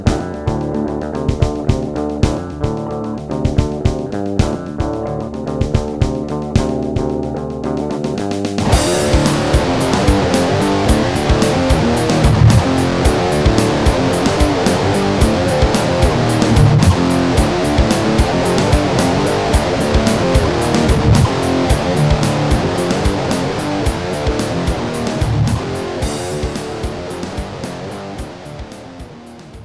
C'est pas du tout du prog, on joue pas vraiment bien, ma voix est pas terrible et pas très juste...
La caisse claire aussi, non?
(c'est un vieil extrait, toute la chanson a été ré-enregistrée depuis, avec un meilleur son, mais ça donne une idée).